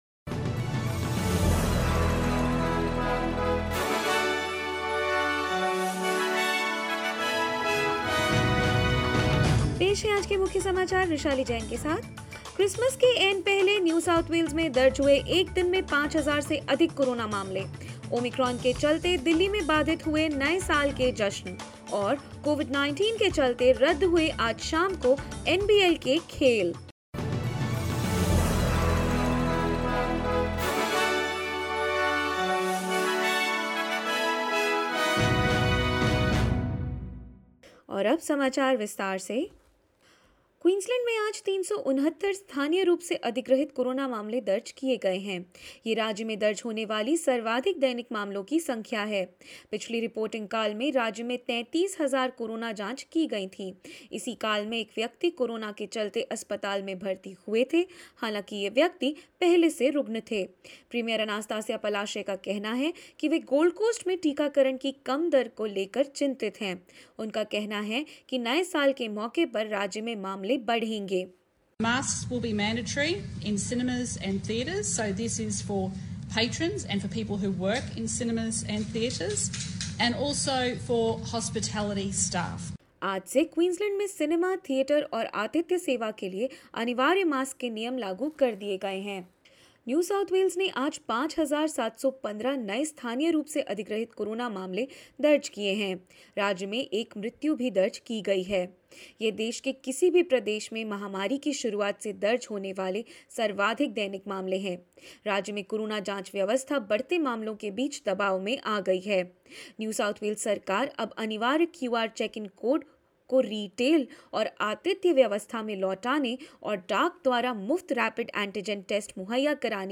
In this latest SBS Hindi news bulletin of Australia and India: New South Wales records its highest daily number of COVID-19 cases, just days out from Christmas; World's first coronavirus pill receives approval in the United States and more.